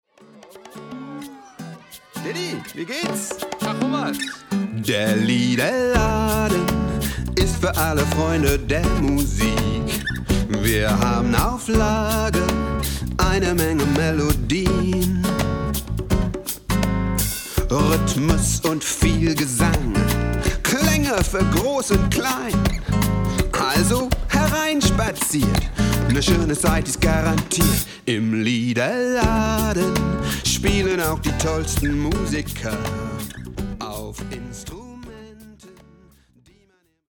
Spiel- und Spaßlieder nicht nur für Geburtstagskinder